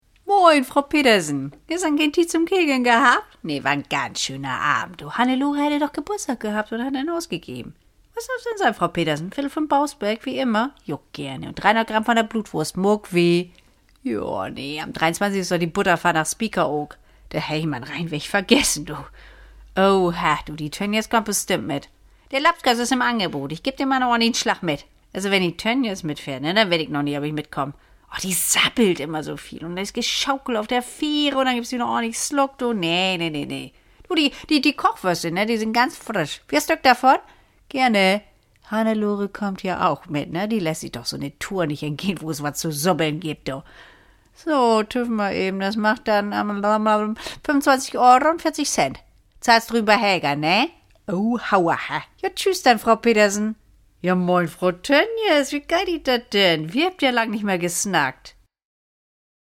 Schauspielerin, Sprecherin
norddeutsch
Sprechprobe: Sonstiges (Muttersprache):